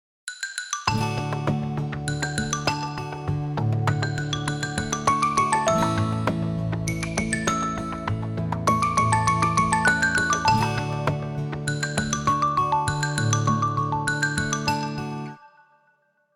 • Качество: 320, Stereo
мелодичные
без слов
колокольчики
звонкие
ксилофон